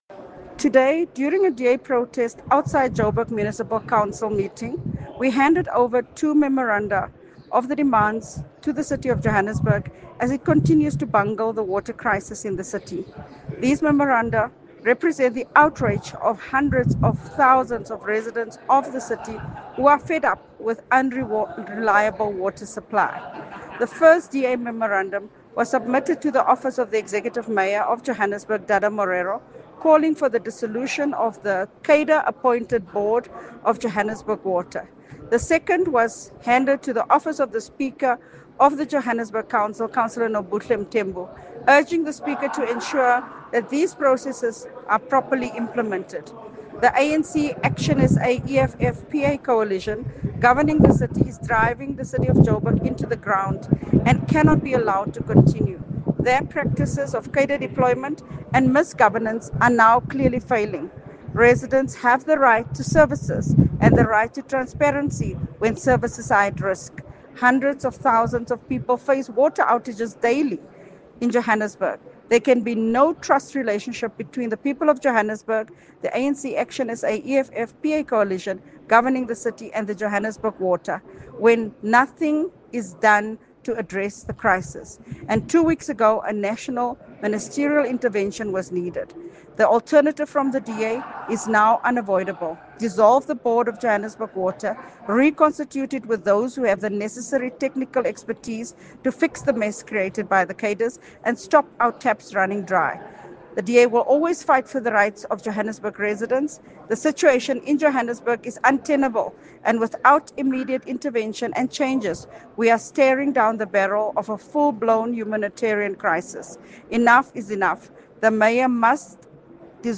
Please find an English soundbite by Cllr Belinda Kayser-Echeozonjoku
Today, during a DA protest outside the Joburg Municipal Council meeting, we handed over two memoranda of demands to the City of Johannesburg as it continues to bungle the water crisis in the city.